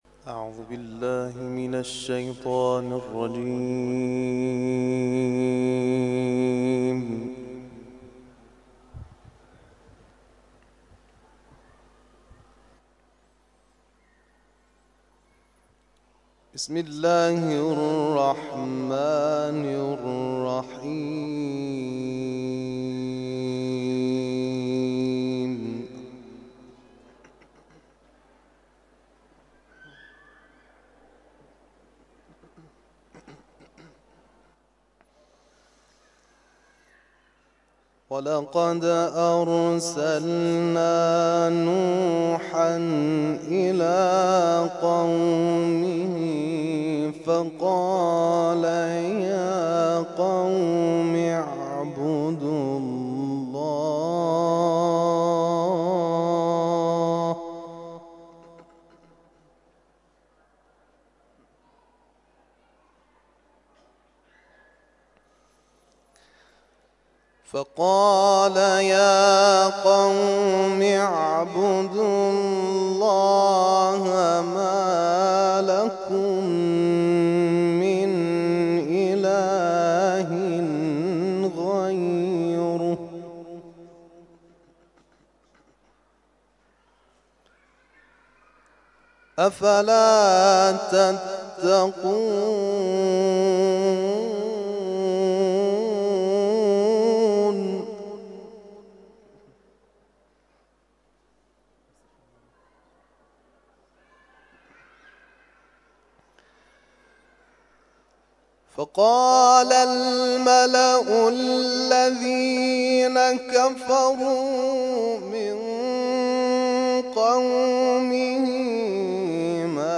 تلاوت ظهر - سوره شعراء آیات ( ۶۹ الی ۸۹ )